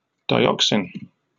Ääntäminen
RP : IPA : /daɪˈɒksɪn/ GA : IPA : /daɪˈɑksən/